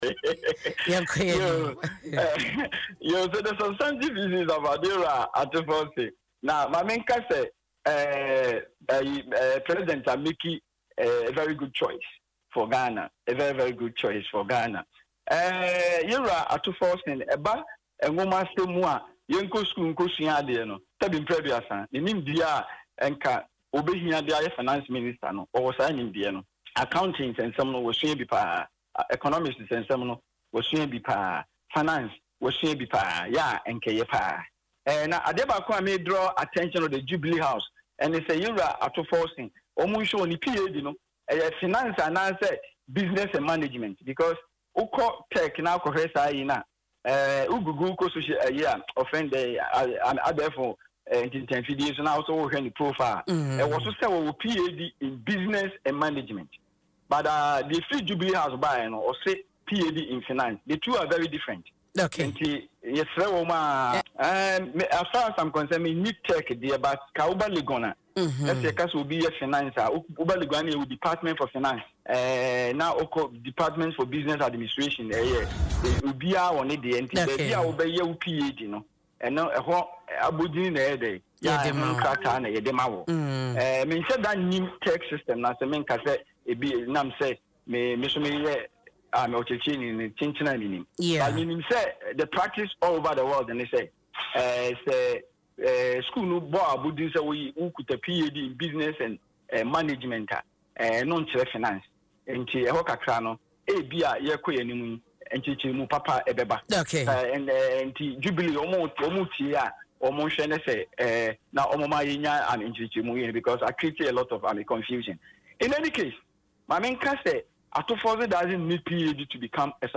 In an interview on Adom FM’s morning show Dwaso Nsem